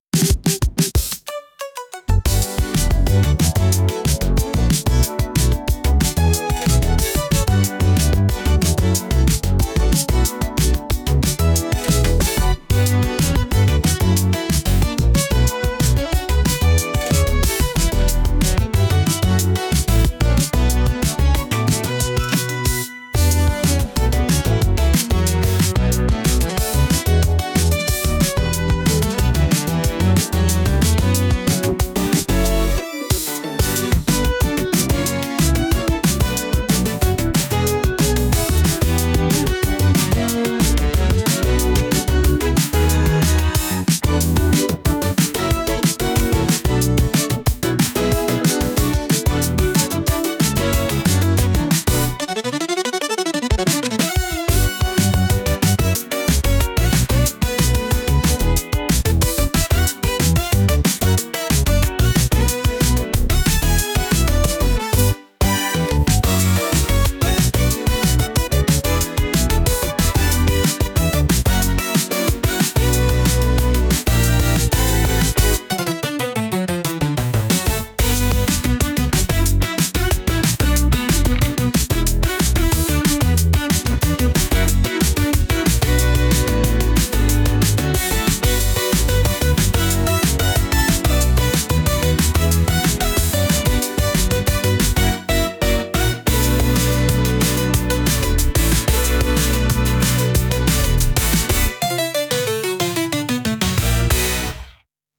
かっこいい エレクトリック キャッチー